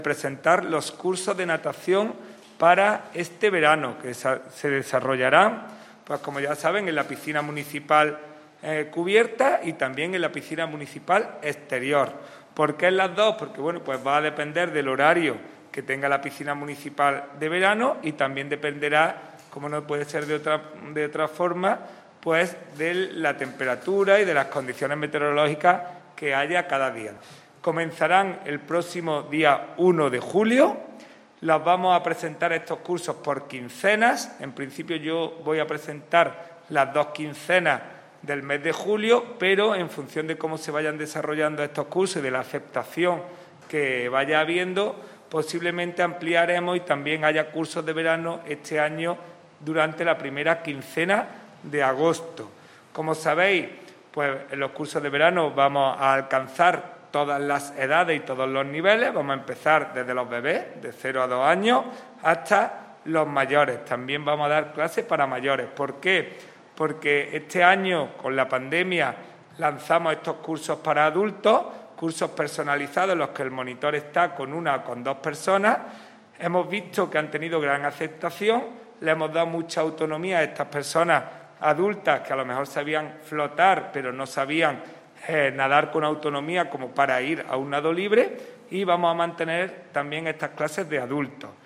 El teniente de alcalde delegado de Deportes, Juan Rosas, ha presentado esta mañana en rueda de prensa otra nueva iniciativa que vuelve a retomarse con la llegada del verano y que sigue con la pretensión de tratar de impulsar a que la población, sea de la edad que sea, haga deporte como hábito saludable.
Cortes de voz